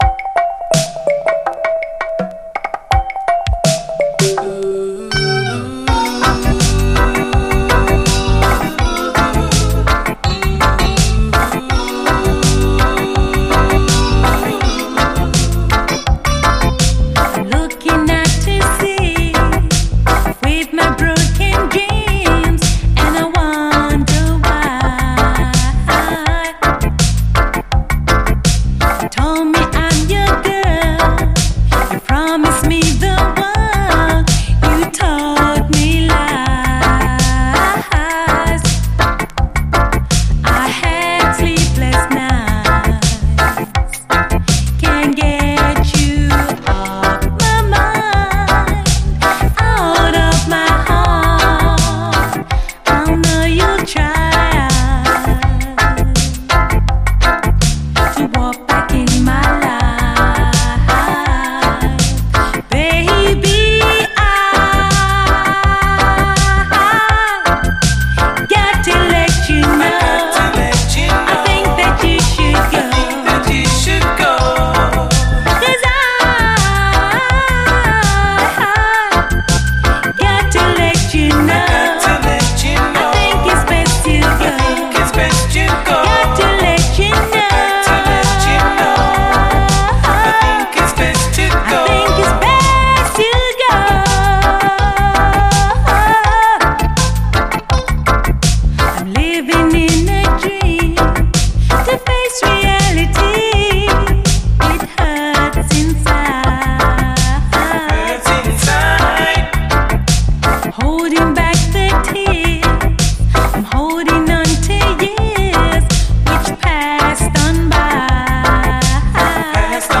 REGGAE
中盤での男性コーラスやフルート・ソロの入り方もナイスなビューティフルUKラヴァーズ！
もトロけるようにスムース。